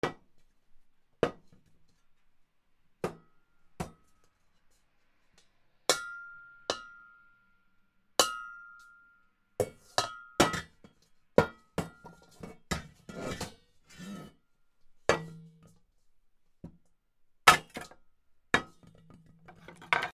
ボールをぶつける
/ G｜音を出すもの / Ｇ-15 おもちゃ
『ト』